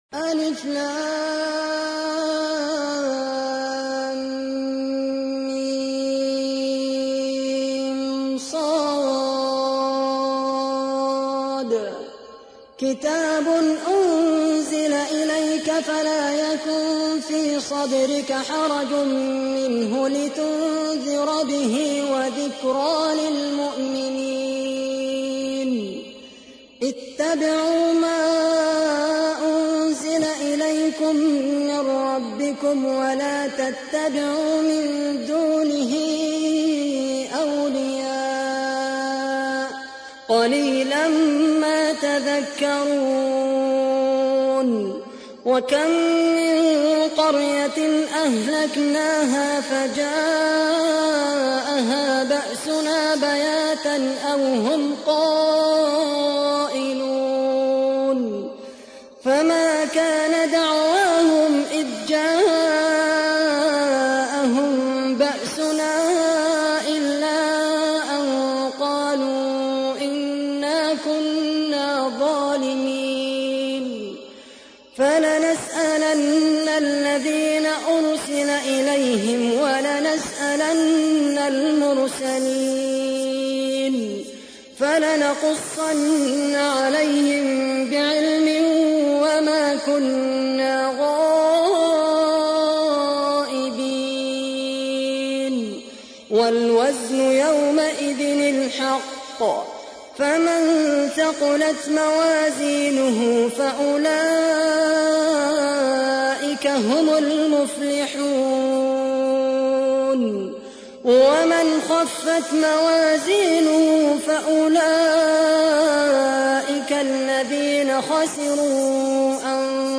تحميل : 7. سورة الأعراف / القارئ خالد القحطاني / القرآن الكريم / موقع يا حسين